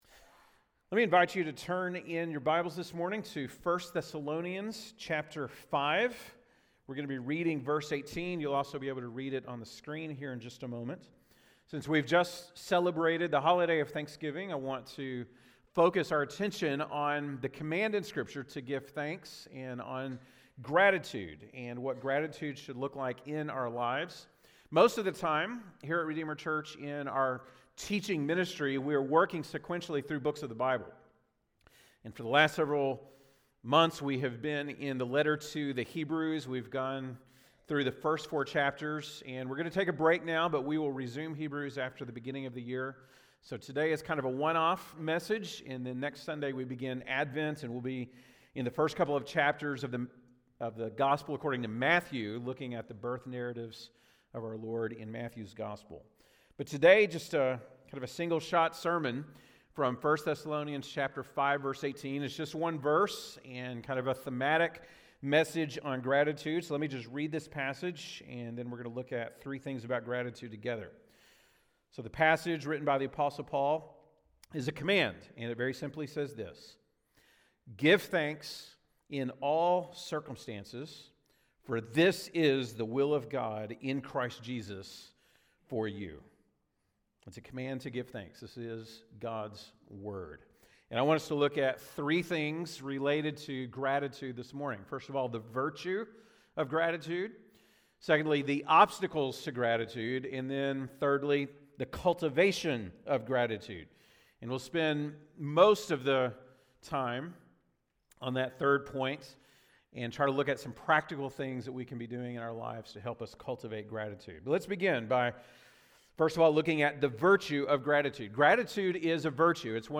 November 26, 2023 (Sunday Morning)